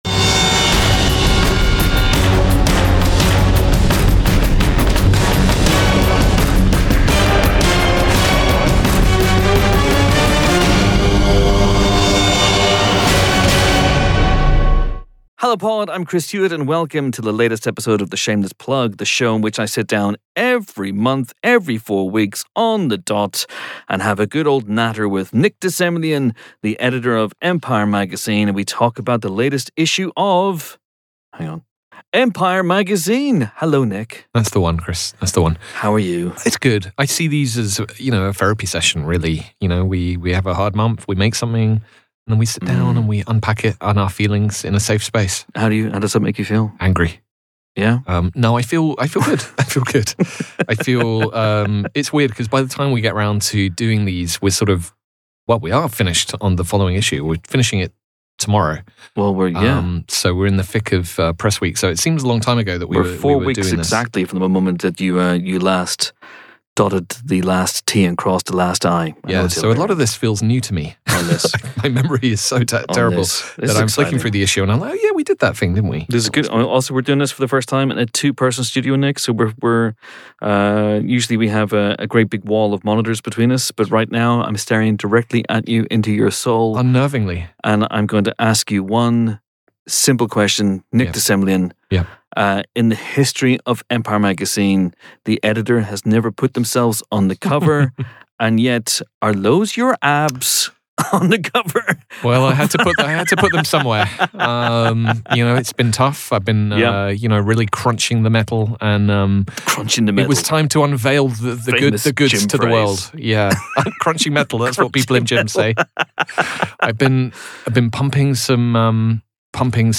read in a Scottish accent